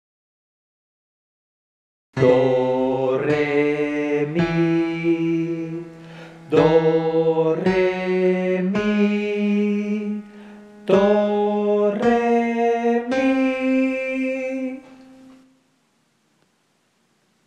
3.2 A escala de tons (Whole tone scale)
Escala formada unicamente por tons.
Como non se distingue ningún ton ten un carácter borroso e indistinto.
escala_de_tons.mp3